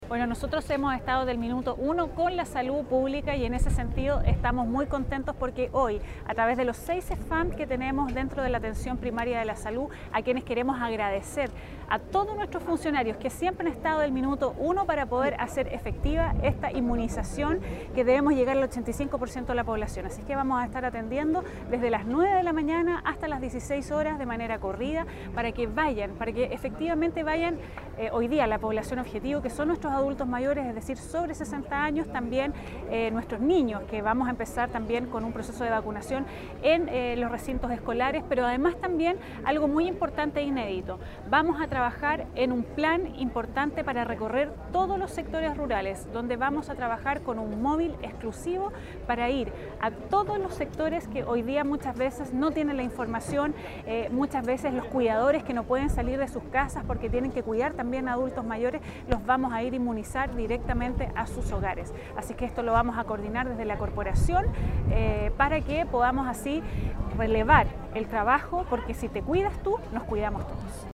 Finalmente, la Alcaldesa de comuna de La Serena, Daniela Norambuena, explicó que en los CESFAM de la comuna los vacunatorios atenderán de 9:00 de la mañana hasta las 16:00 hrs. en forma continua, y además, informó sobre una iniciativa pionera para el sector rural
CUNA-ALCALDESA-DE-LA-SERENA_VACUNACION.mp3